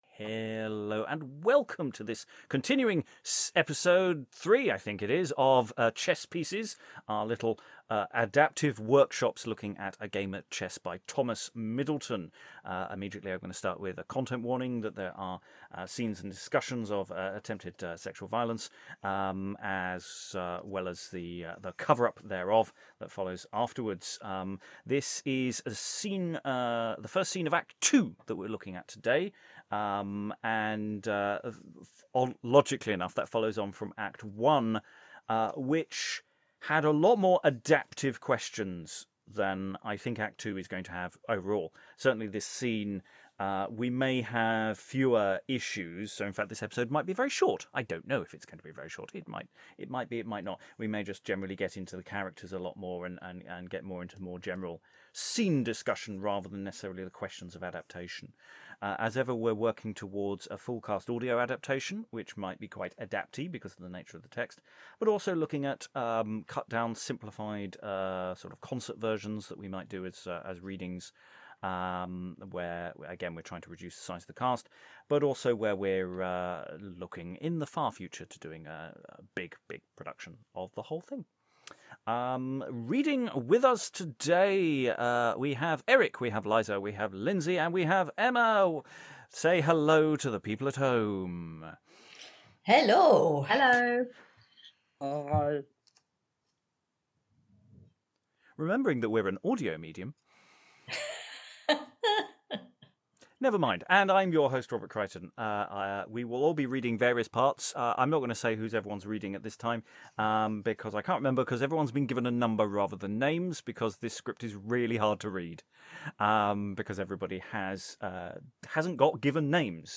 Chess Pieces: Exploring A Game at Chess by Thomas Middleton A planning session for our future productions of A Game at Chess by Middleton.